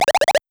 Speaking.wav